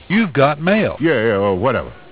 WHATEVER MAIL You thought it was over with all the mail sounds.......